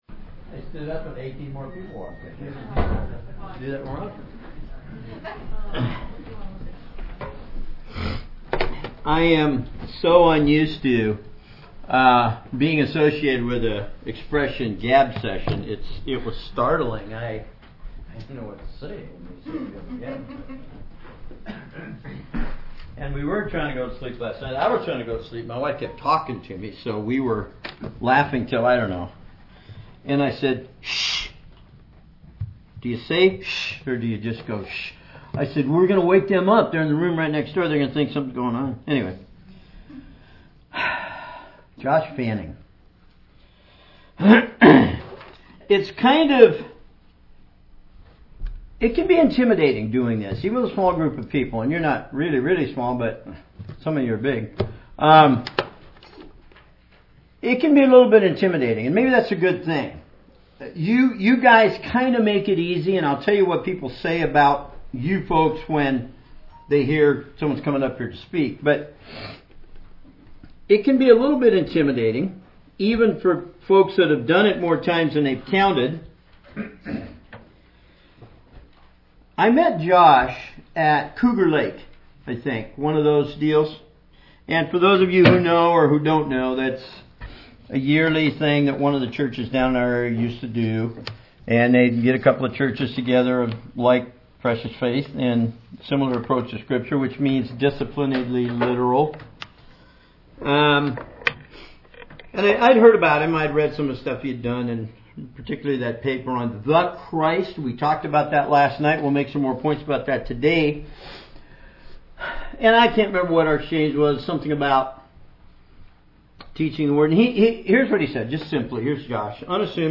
Bible Conference 2019